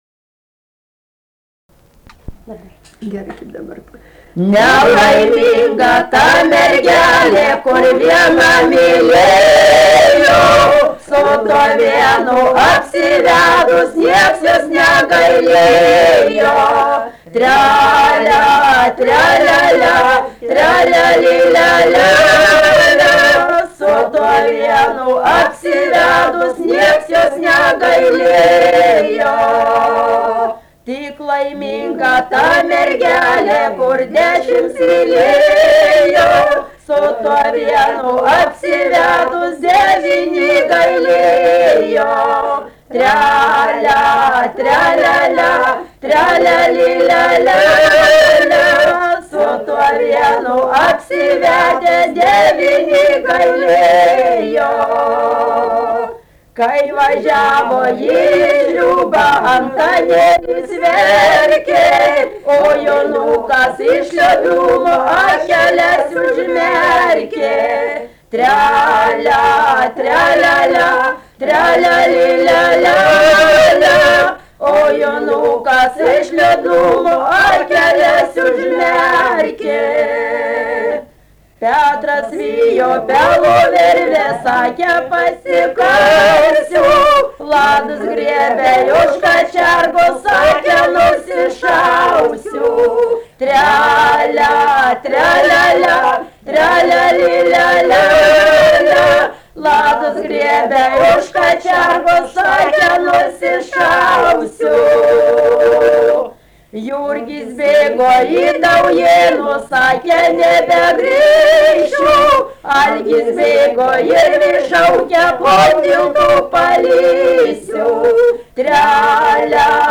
daina
Daujėnai
Jukoniai
vokalinis